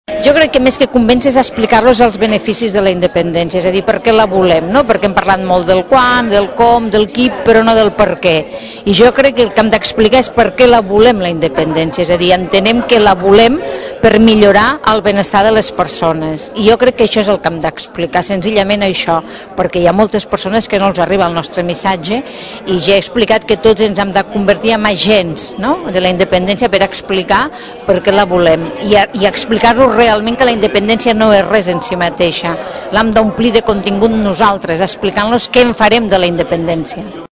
Durant el discurs, emmarcat en el títol de “Moments Decisius”, Carme Forcadell va destacar que, part de l’èxit del 27-S, passa per convèncer els indecisos, i explicar-los el perquè de voler la independència i els beneficis que pot aportar a Catalunya.